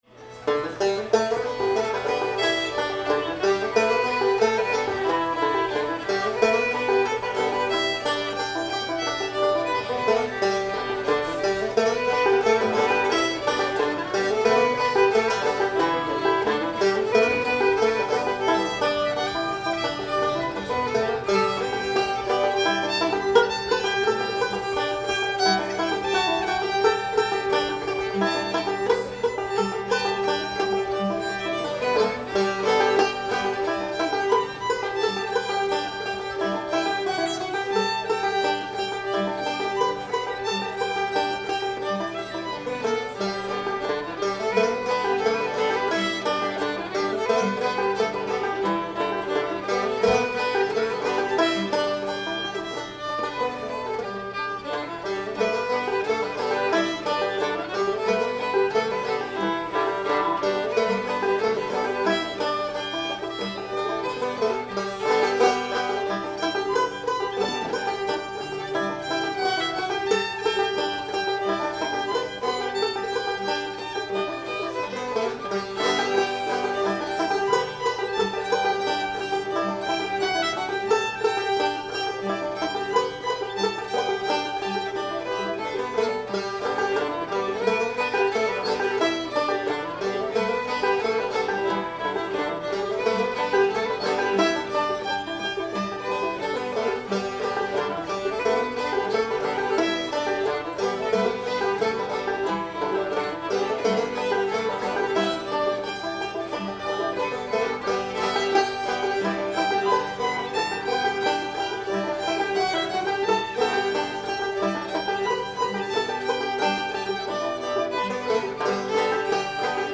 sandy river belle [G]